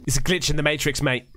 glitch in the sound effects